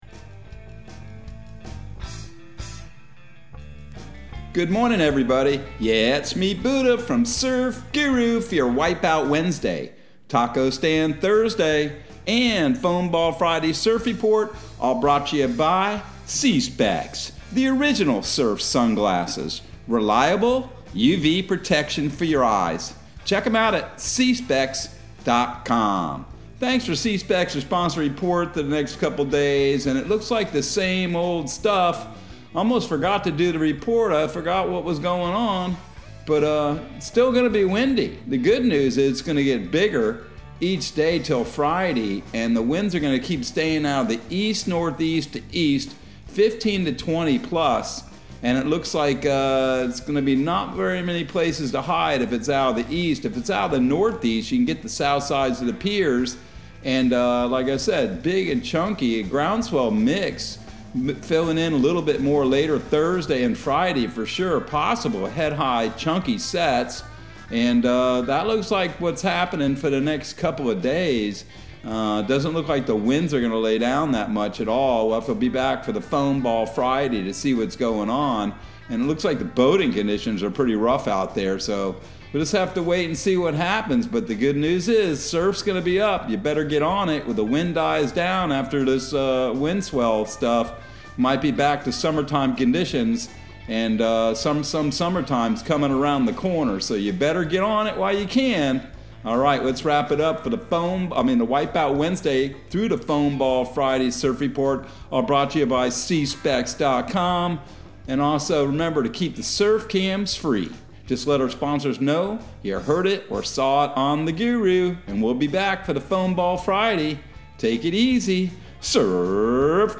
Surf Guru Surf Report and Forecast 04/20/2022 Audio surf report and surf forecast on April 20 for Central Florida and the Southeast.